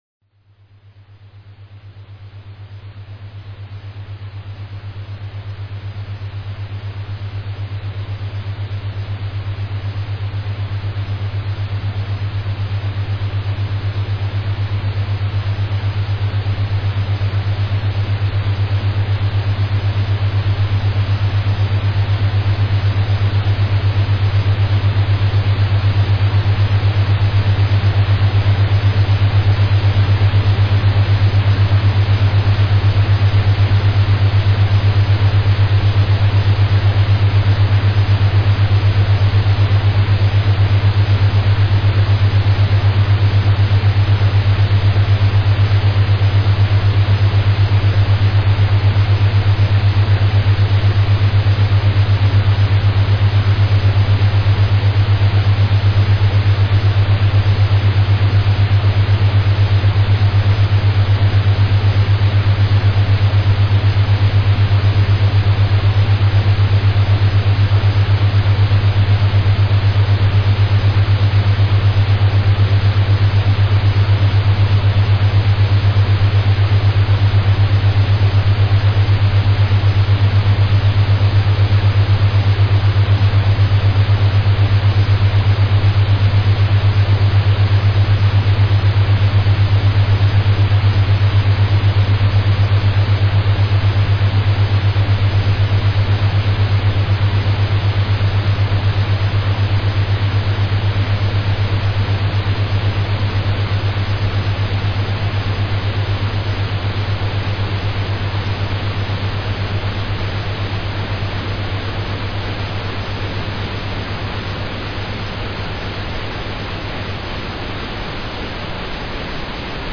Сначала спокойное начало…тихо…тихо…медлено..И тут вы начинаете чувствовать как ваш мозг начинает разгон, множество новых мыслей, куча новых идей!